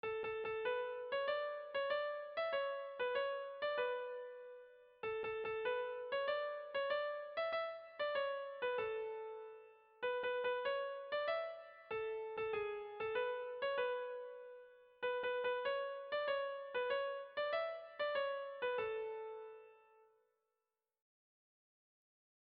Irrizkoa
Zortziko txikia (hg) / Lau puntuko txikia (ip)
A1A2B1B2